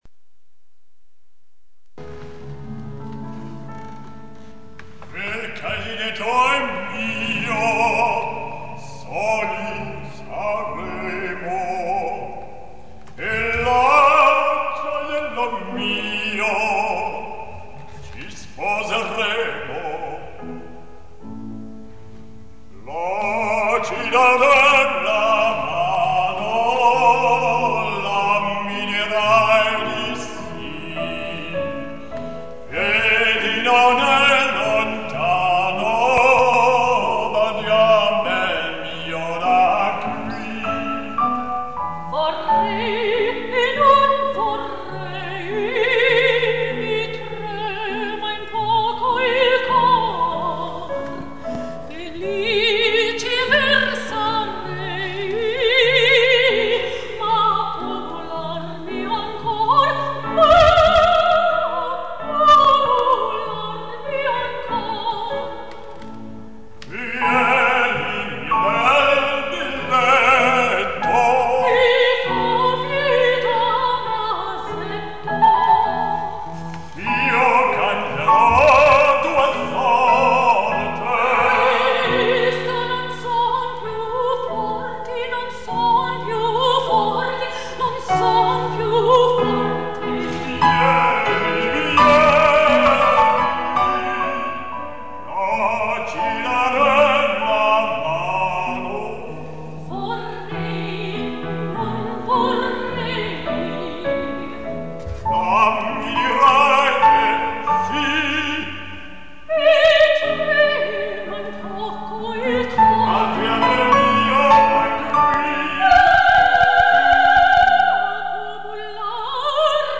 RECITAL  PRESTIGO
soprano
baryton
au piano